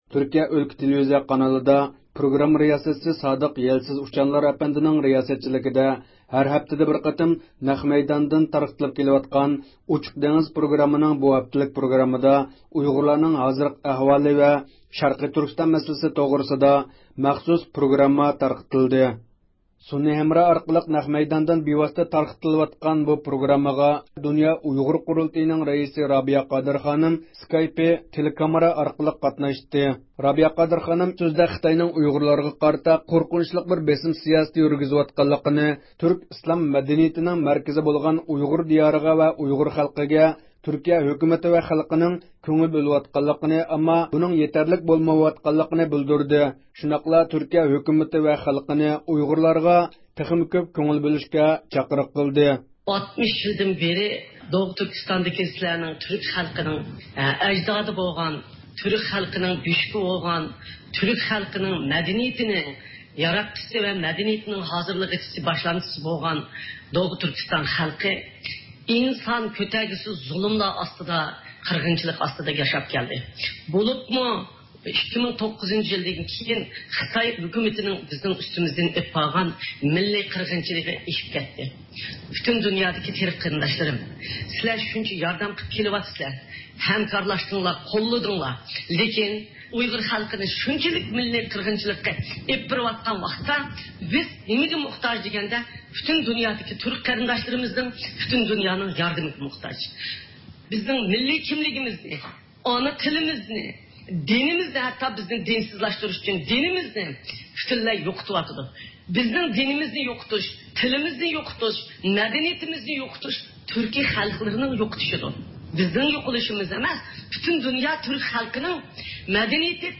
سۈنئىي ھەمراھ ئارقىلىق نەق مەيداندىن بىۋاسىتە تارقىلىۋاتقان بۇ پروگراممىغا دۇنيا ئۇيغۇر قۇرۇلتىيىنىڭ رەئىسى رابىيە قادىر خانىم سىكايپى تېلېكامېراسى ئارقىلىق قاتنىشىپ سۆز قىلدى.